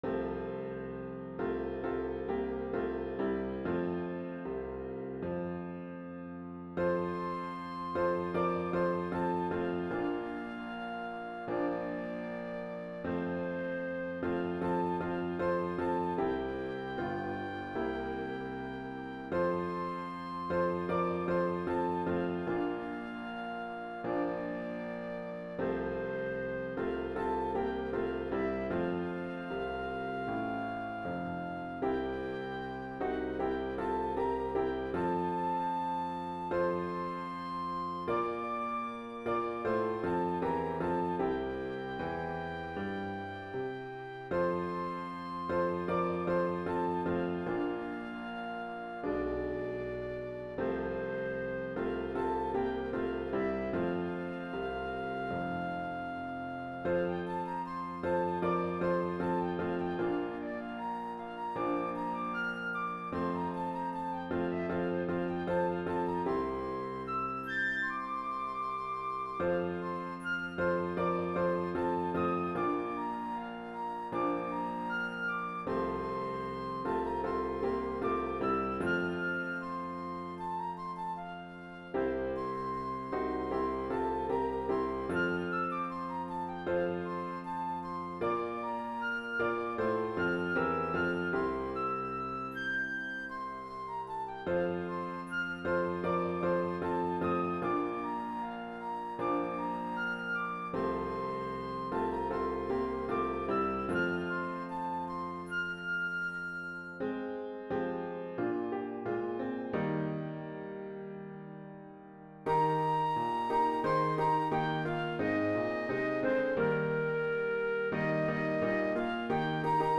Medley of Three Hymns for Flute and Piano
Here is a medley of all three pieces.
HYMN MUSIC